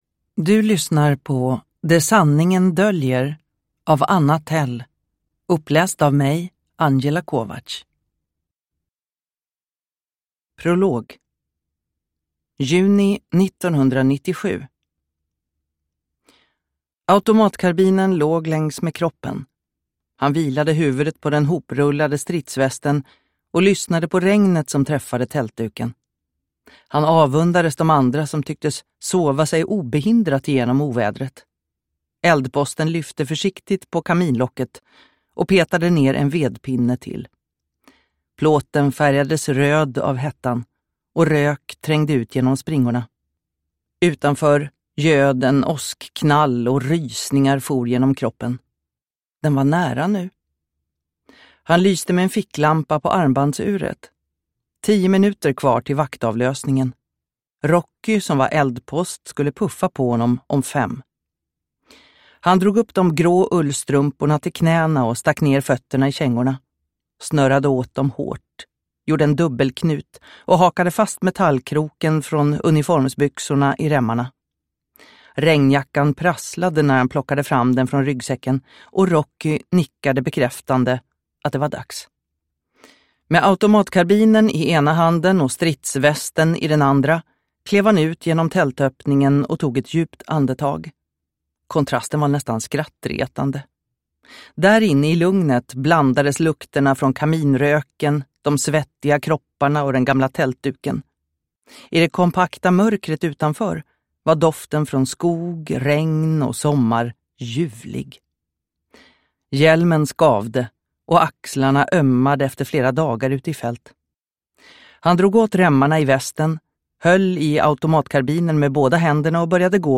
Det sanningen döljer – Ljudbok – Laddas ner